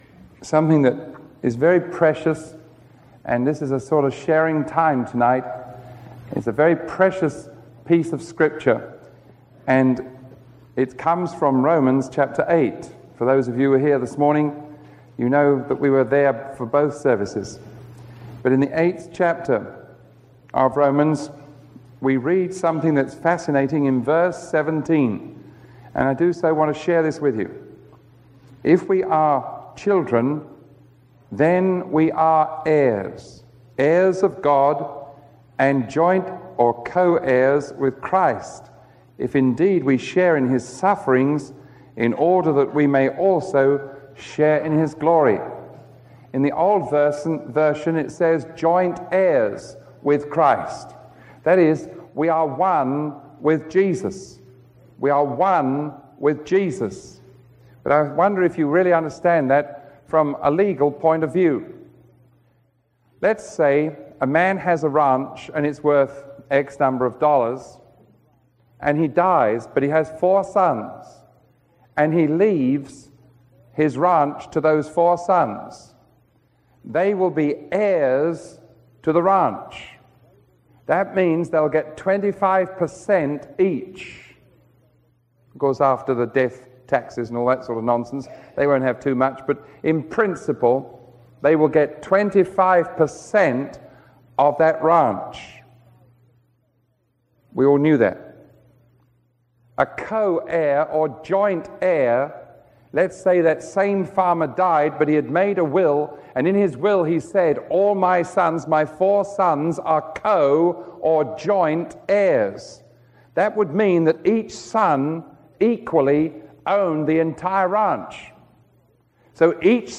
Biblical Teachings